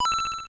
Sound effect of Coin in Mario Bros. (NES version).